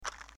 box_pickup.mp3